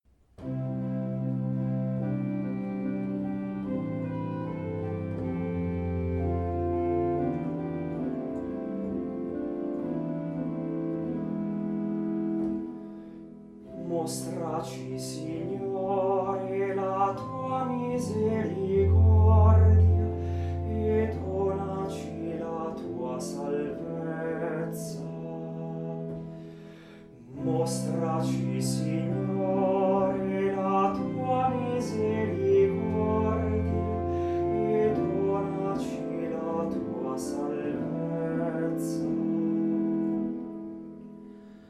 Audio Esecuzione Ensemble sicut in caelo